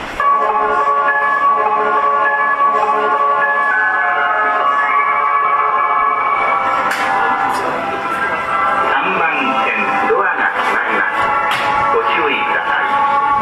ただ高架下なのでうるさく、一部音質が悪いホームがあり、玉に瑕です。
チャイム